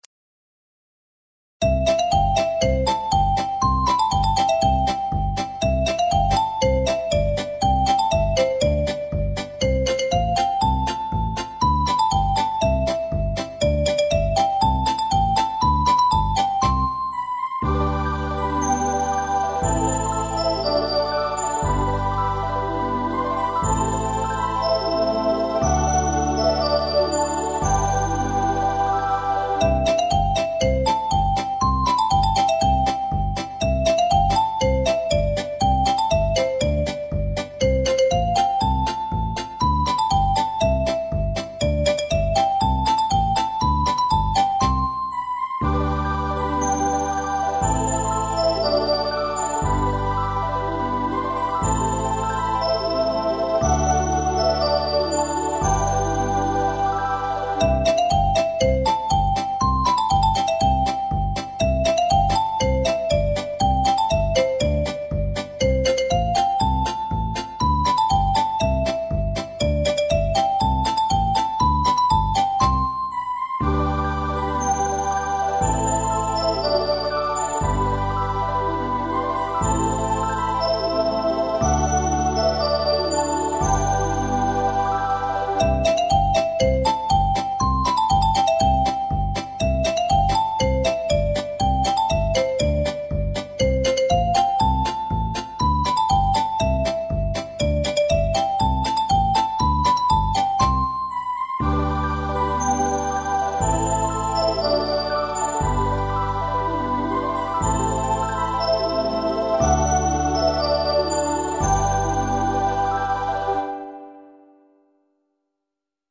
• 【促销音乐】纯音乐 - 轻快活泼音乐